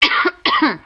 cough1.wav